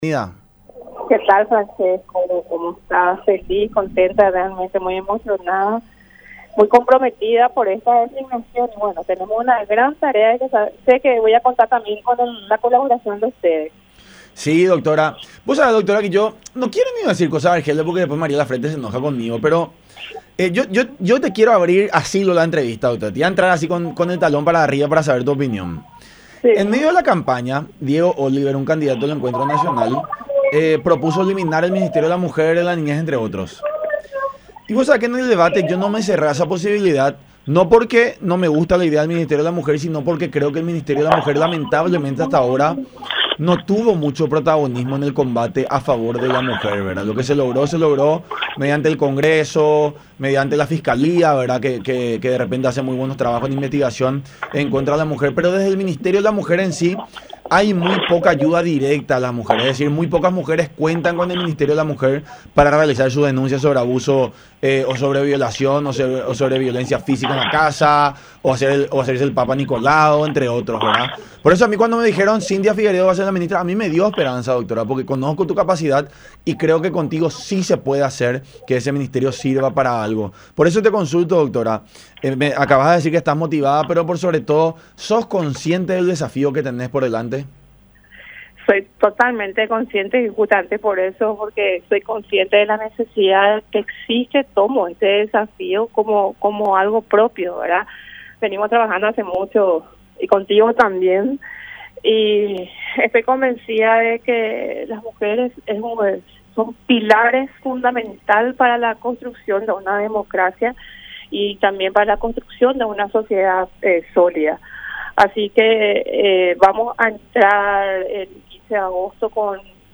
“El empoderamiento económico de las mujeres, la autonomía de las mujeres y por sobre todo una vida sin violencia son cosas que nos urge”, mencionó Cynthia Figueredo en el programa “La Unión Hace La Fuerza” por Unión Tv y radio La Unión.